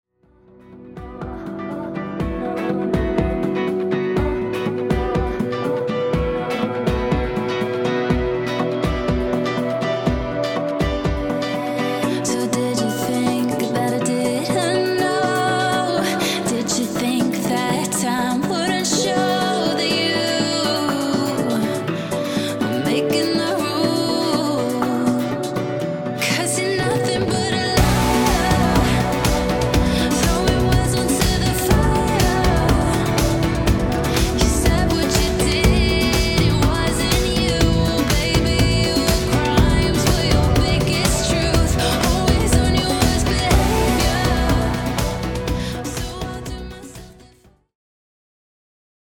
Pop/Dance